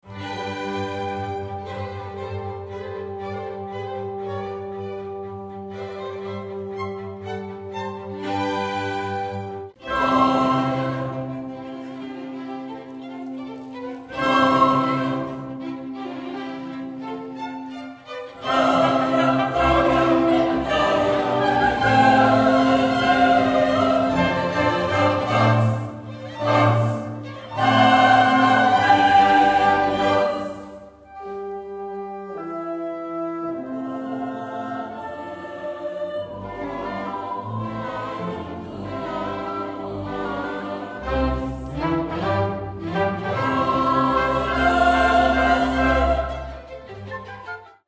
ukázka záznamu koncertu -
kostel-horni_libchava-2007leto-ukazka.mp3